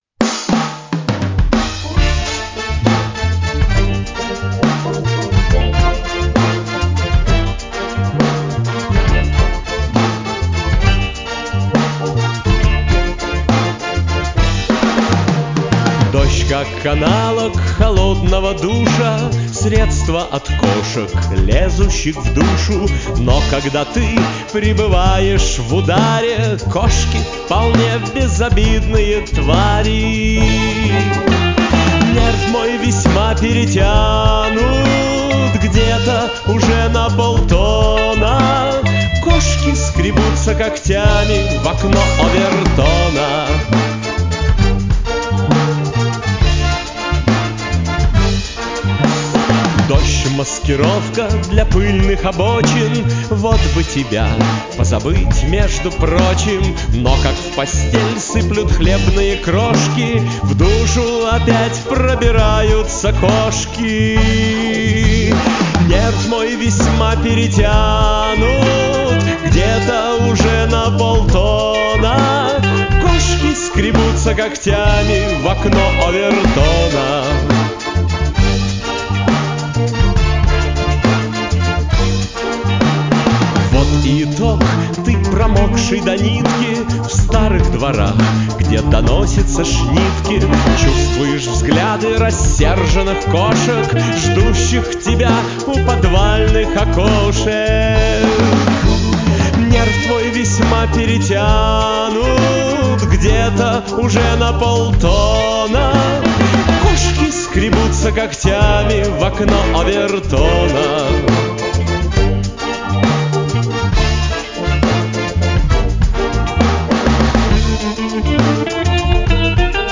Небольшой рыбаланс. Ударные кривые и из за неслышимости хета и бочки, один лысый снейр. И бас играет стаккато не там где надо.